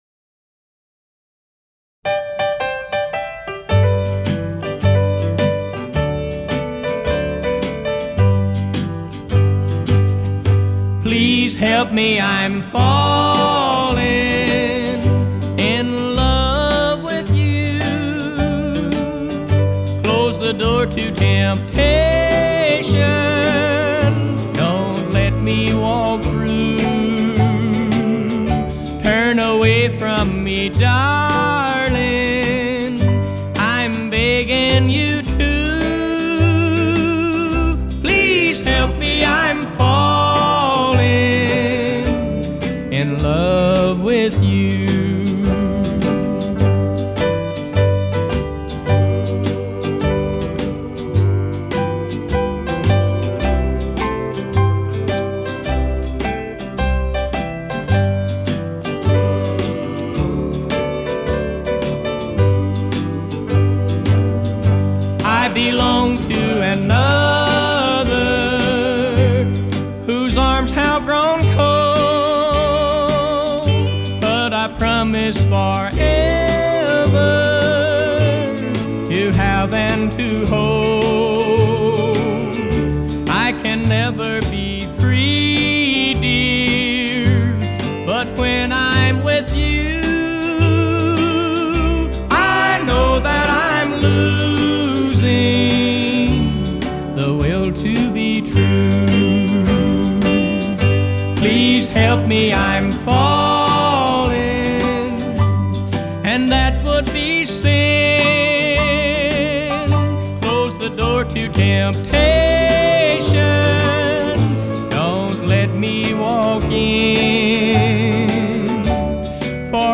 Style: Oldies 50's - 60's / Rock' n' Roll / Country